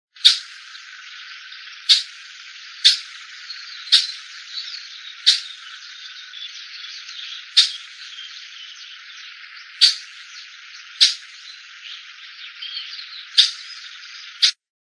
Scientific name: Myiodynastes maculatus solitarius
English Name: Streaked Flycatcher
Life Stage: Adult
Location or protected area: Reserva Ecológica Costanera Sur (RECS)
Condition: Wild
Certainty: Photographed, Recorded vocal